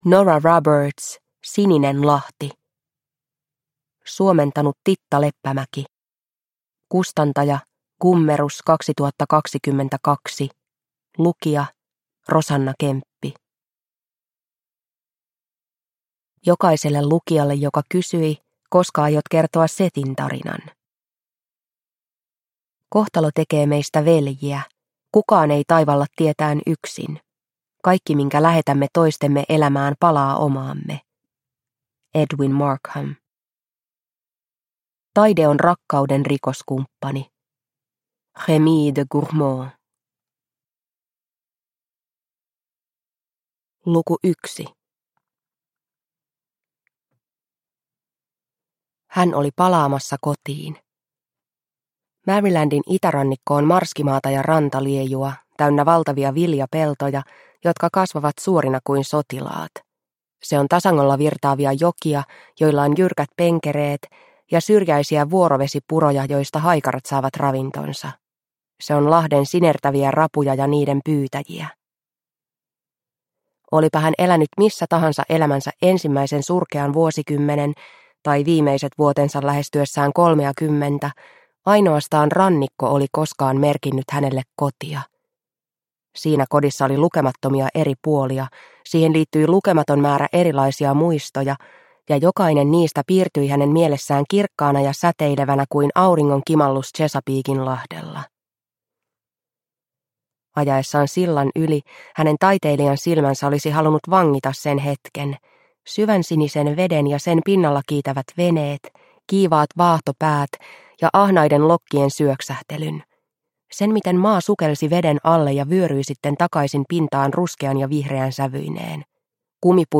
Sininen lahti – Ljudbok – Laddas ner